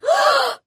Shocking Gasp Sound Effect Free Download
Shocking Gasp